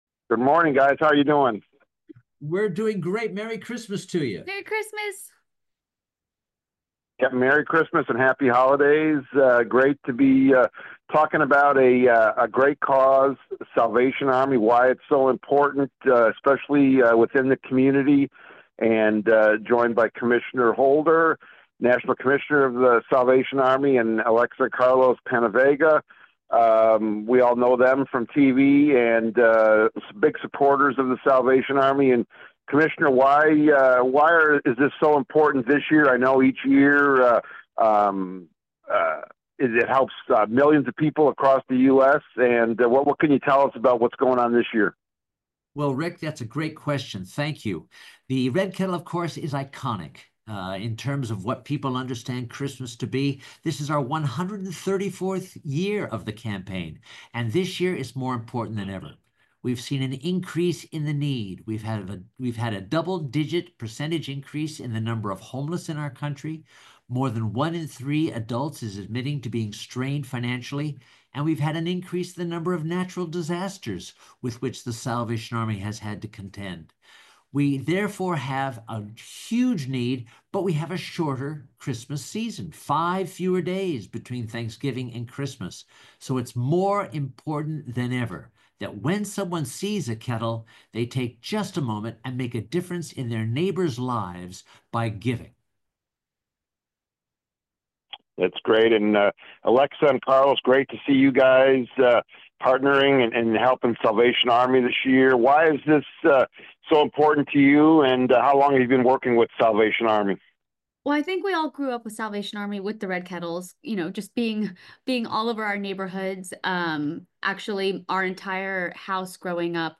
Alexa & Carlos PenaVega – celebrity couple who volunteer for The Salvation Army